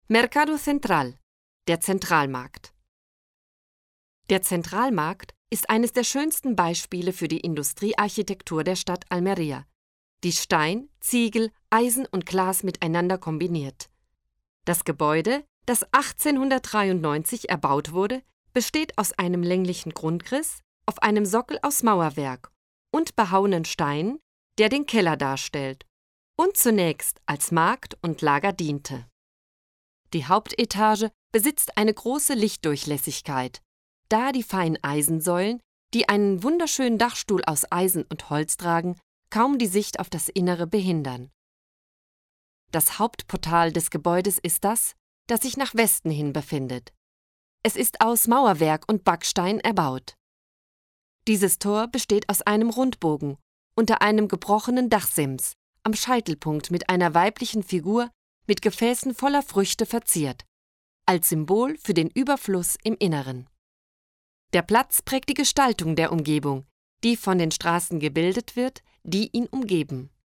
AUDIOGUIA-ALMERIA-ALEMAN-14-mercado-central.mp3